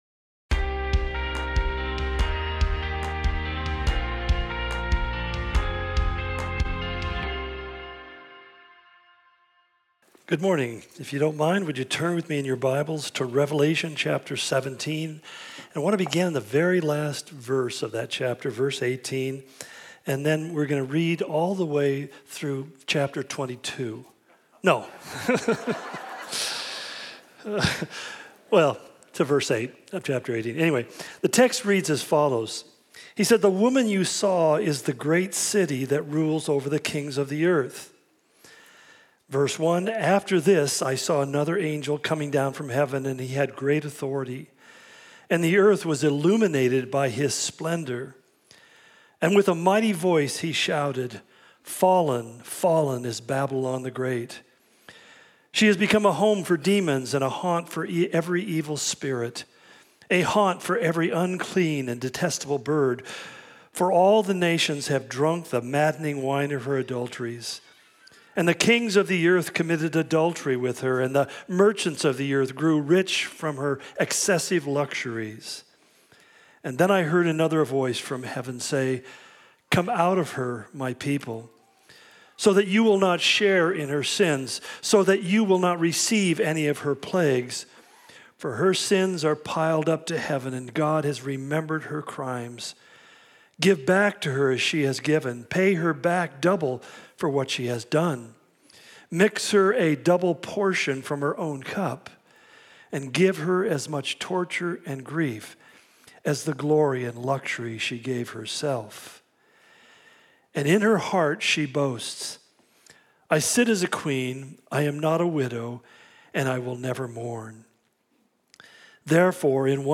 Calvary Spokane Sermon Of The Week podcast To give you the best possible experience, this site uses cookies.